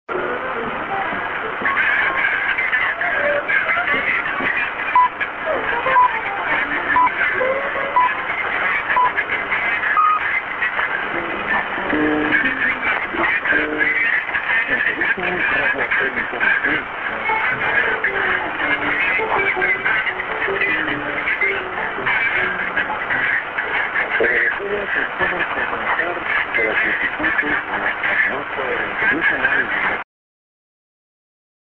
->TS->SJ->ID(man)